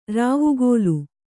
♪ rāvugōlu